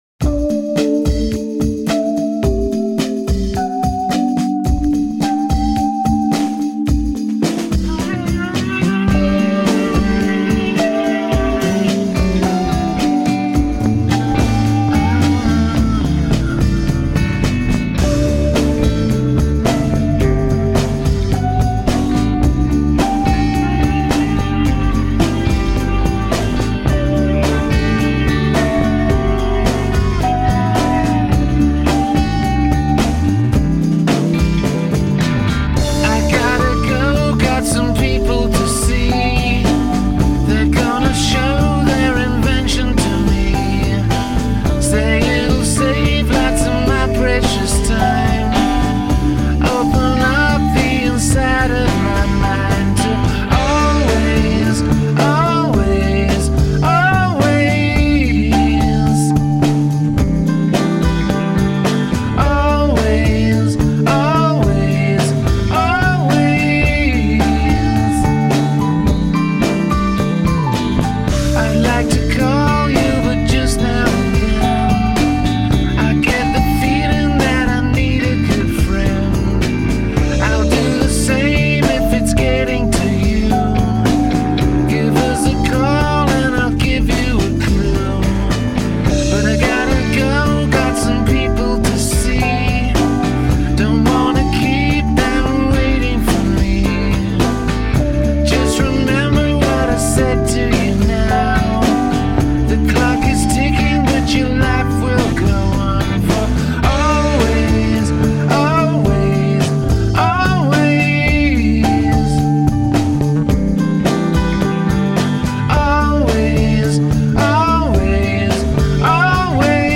The title, the song’s storyline, the mid-tempo groove.